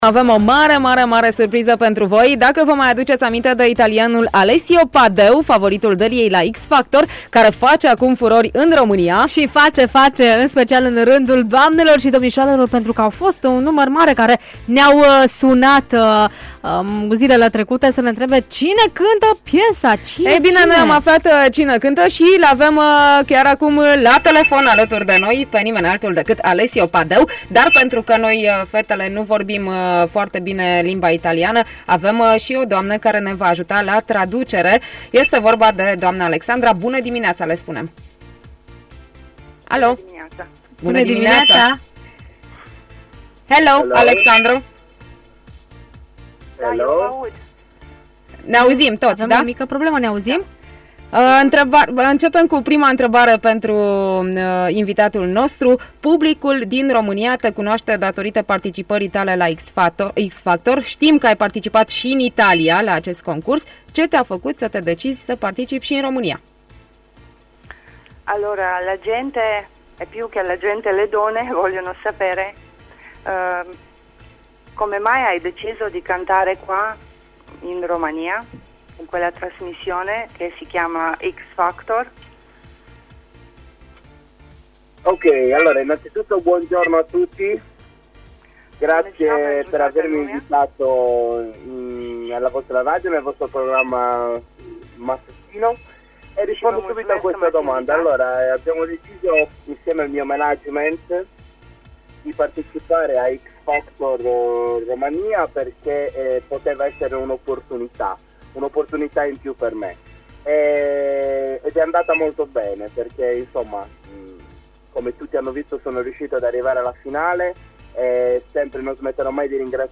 a fost invitatul special în această dimineață la Bună dimineața Banat pe 105,6 FM unde a vorbit despre muzica sa, România și fetele din România.